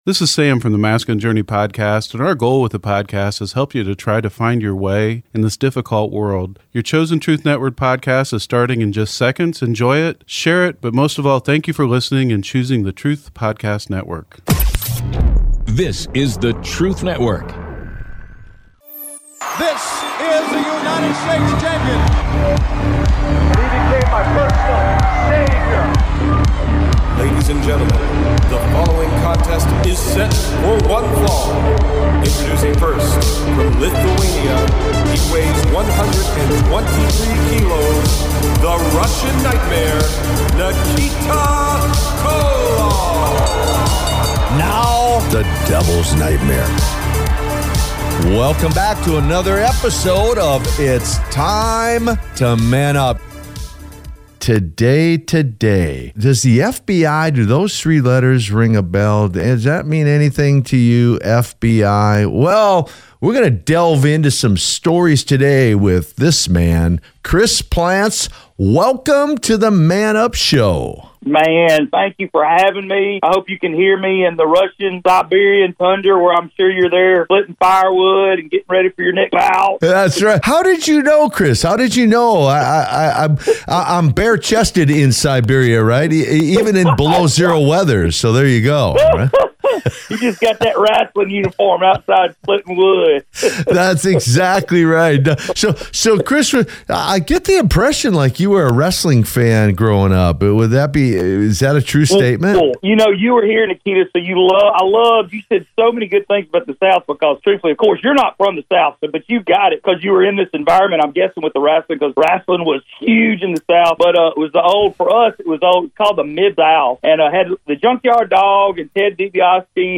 was a special guest on It's Time To Man Up! with Nikita Koloff